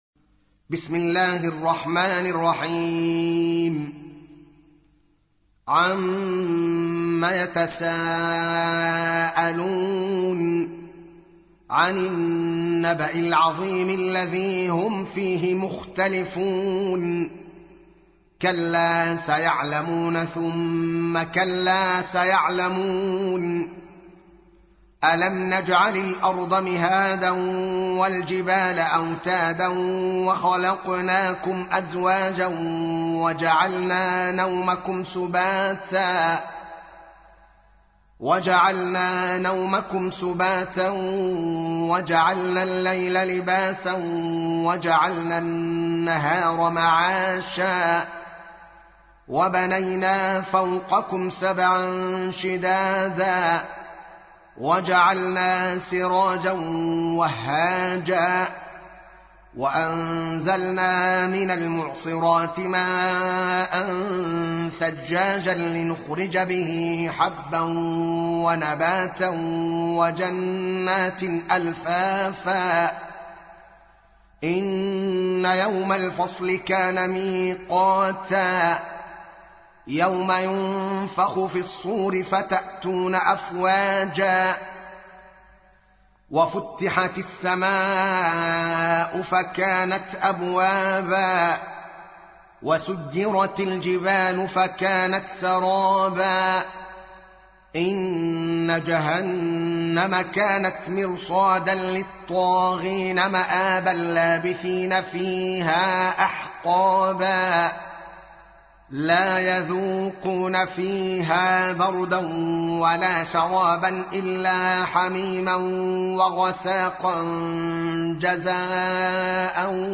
تحميل : 78. سورة النبأ / القارئ الدوكالي محمد العالم / القرآن الكريم / موقع يا حسين